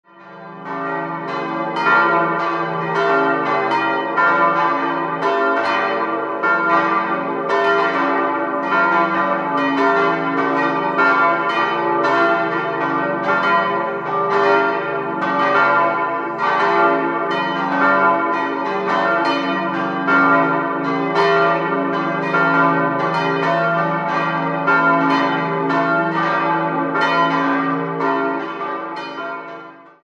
Idealquartett: d'-f'-g'-b' Die vier Glocken wurden 1947 von der Gießerei Otto in Bremen-Hemelingen gegossen.